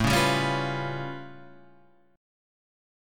A Augmented 9th
A+9 chord {5 4 5 6 6 7} chord